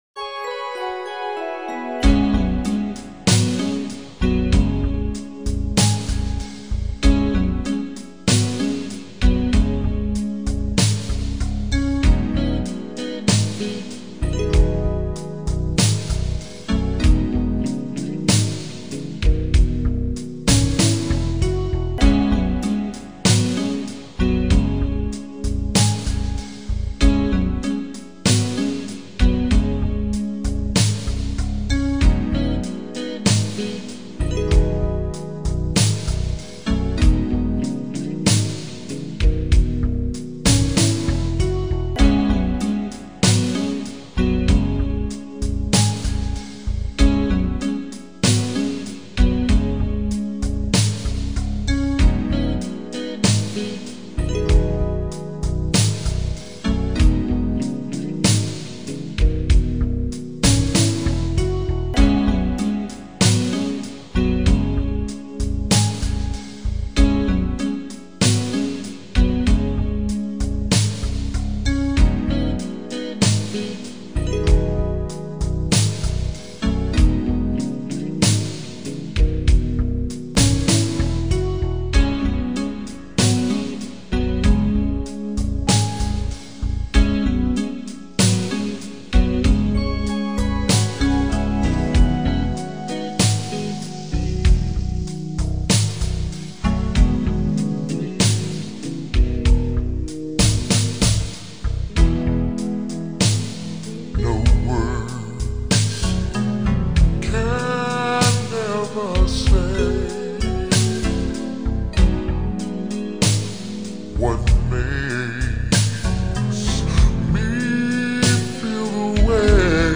vaporwave